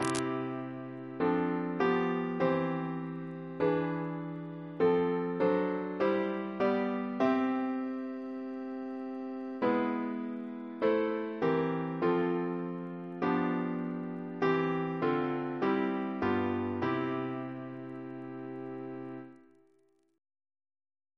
Double chant in C Composer: Charles Harford Lloyd (1849-1919), Organist of Gloucestor Cathedral amd Christ Church, Oxford, Precentor of Eton, Organist and composer to the Chapel Royal